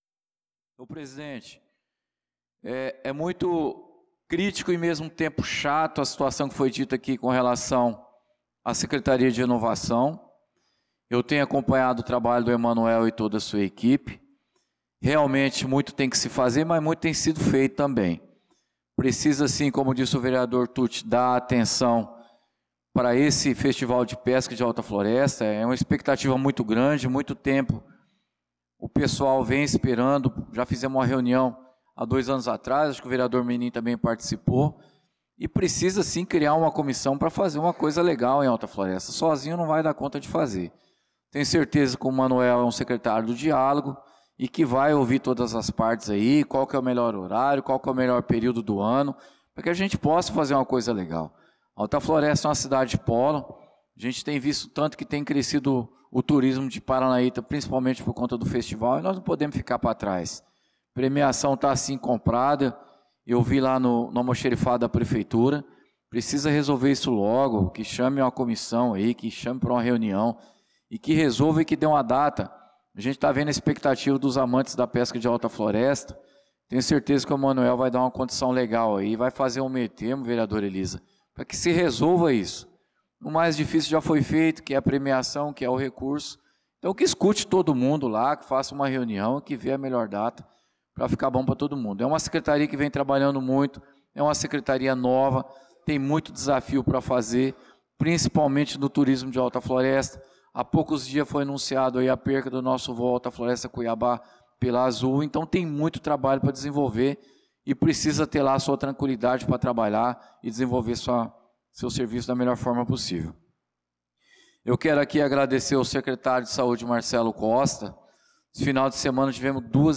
Pronunciamento do vereador Claudinei de Jesus na Sessão Ordinária do dia 23/06/2025.